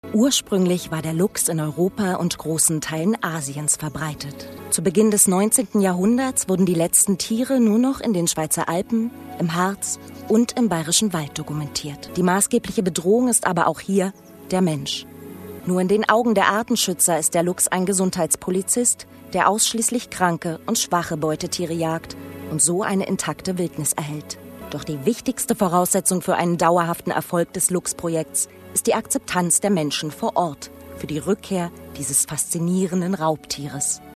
Meine Stimme ist frisch und jung. Seriös und warm. Und manchmal lasziv.
Sprechprobe: eLearning (Muttersprache):
My voice is fresh and young. Serious and warm. And sometimes lascivious.
TV-Doku Luchse.MP3